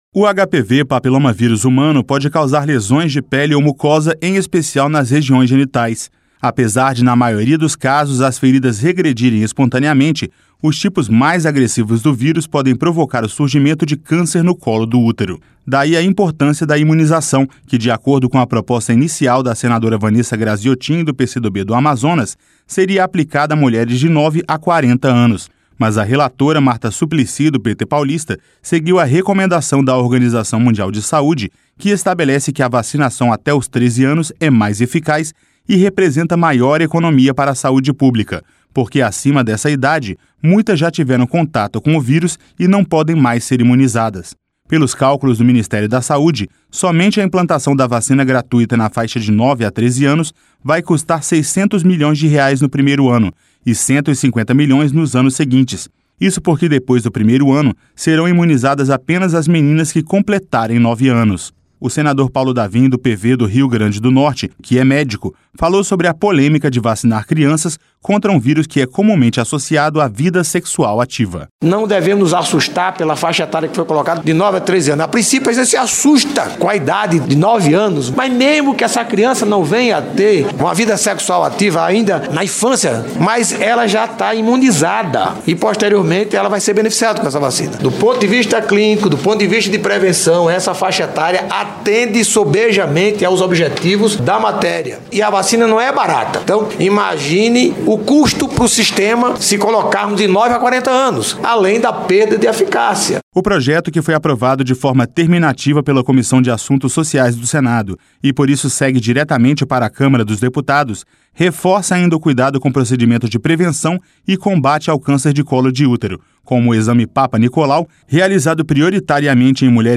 O senador Paulo Davim, do PV do Rio Grande do Norte, que é médico, falou sobre a polêmica de vacinar crianças contra um vírus que é comumente associado à vida sexual ativa.